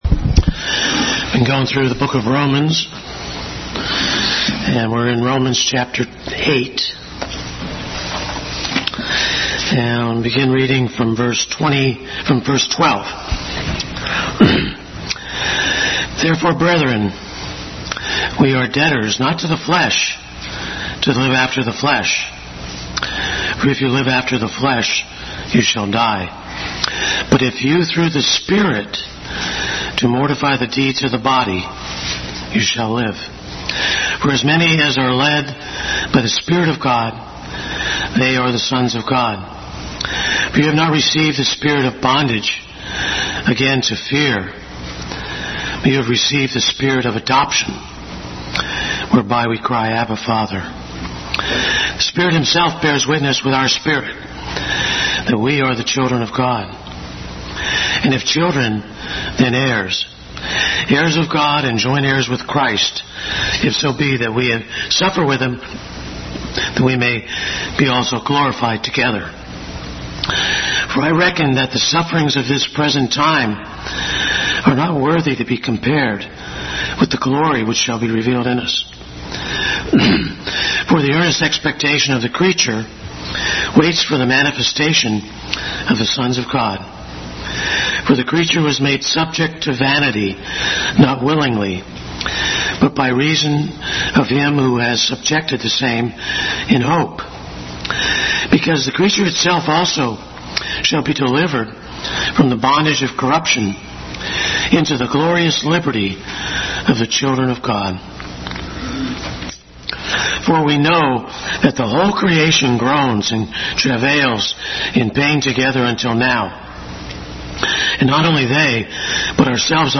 Romans 8:12-27 Service Type: Sunday School Bible Text